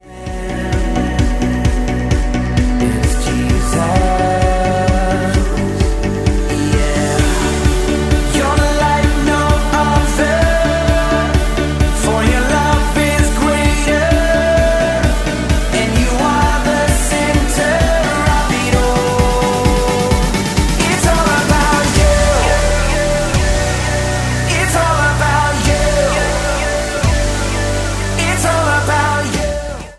élő albuma